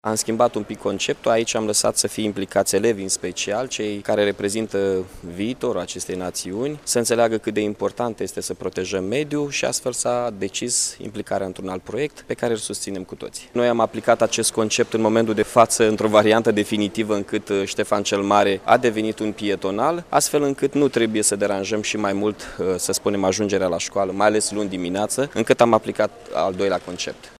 Viceprimarul Iașului, Mihai Chirica a precizat că cea mai mare parte a elevilor de la școlile ce au intrat  în acest proiect au preferat să vină la ore cu mijloacele de transport în comun, fără să apeleze la ajutorul părinților care de obicei îi aducea cu mașina.